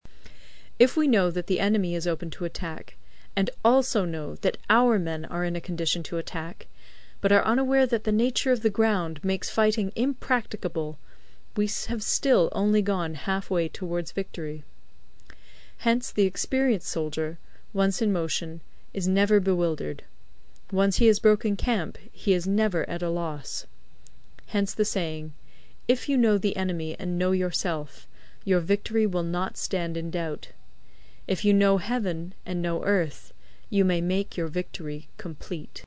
有声读物《孙子兵法》第61期:第十章 地形(5) 听力文件下载—在线英语听力室